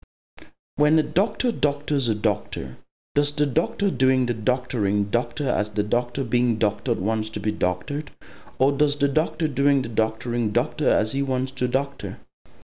A tongue-twister is a phrase that is designed to be difficult to articulate properly, and can be used as a type of spoken (or sung) word game.